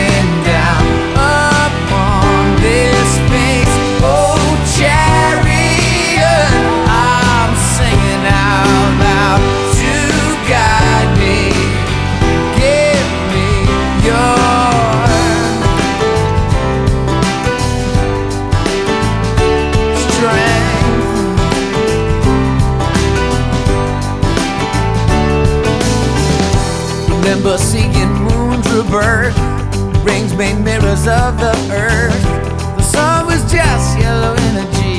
Жанр: Поп / Рок / Альтернатива